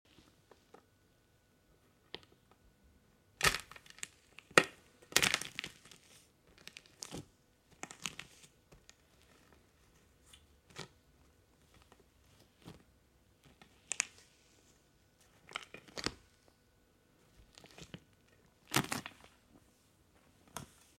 Gooey Easter Egg Crush! 🐣👣 sound effects free download